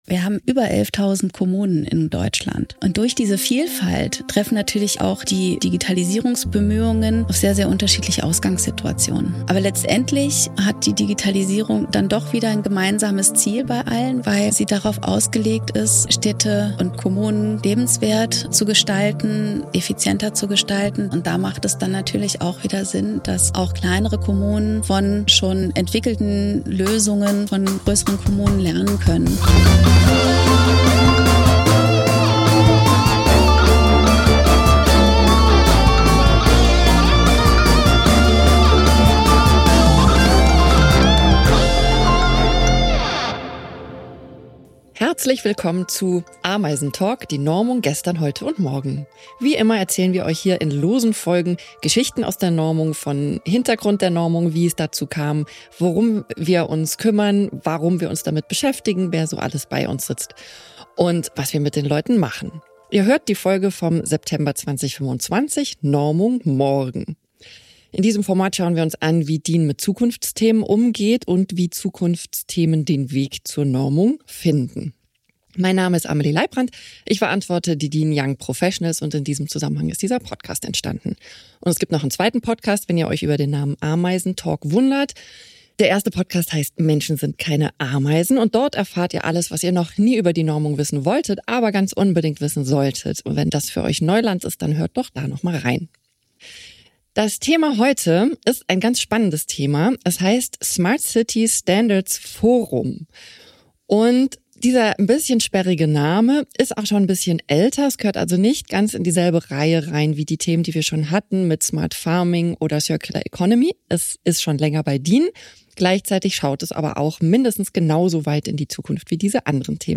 In dieser Interviewreihe stellen wir euch echte DINies vor - Mitarbeitende von DIN, die spannende Normungsgeschichten erzählen.